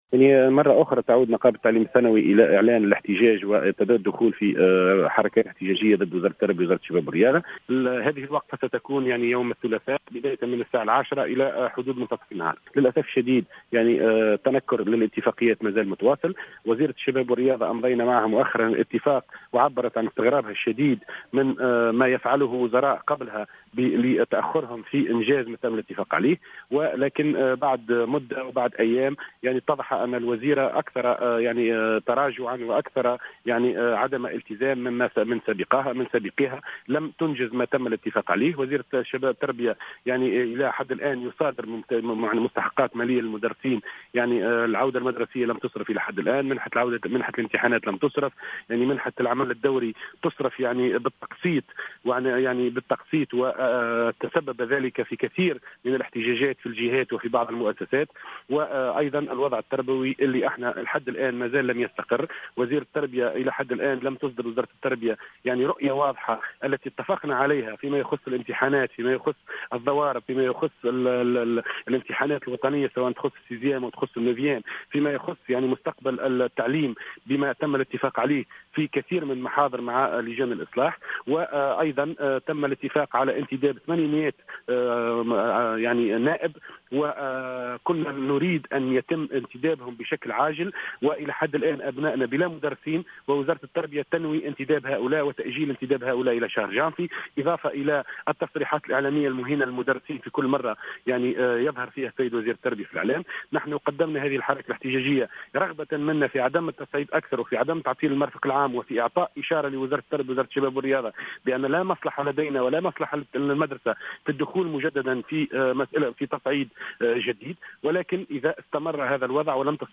في تصريح للجوهرة "اف ام"